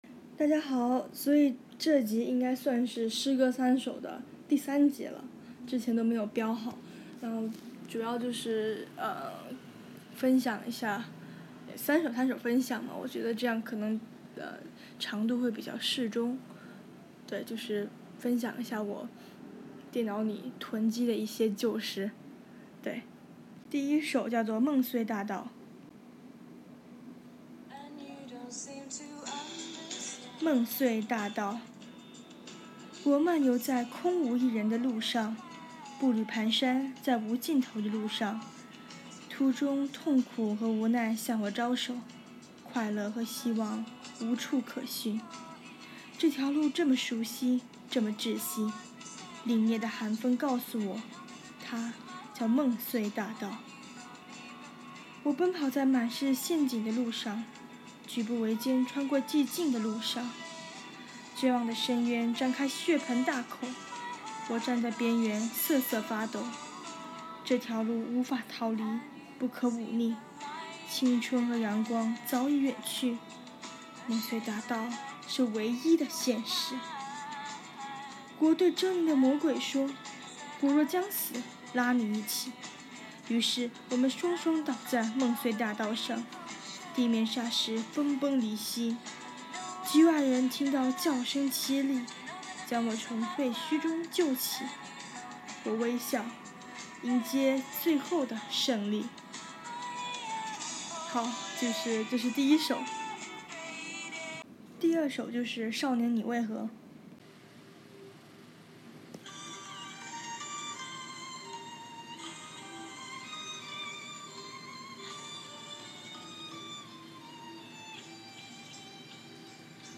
PS: 背景音乐怎～么～样～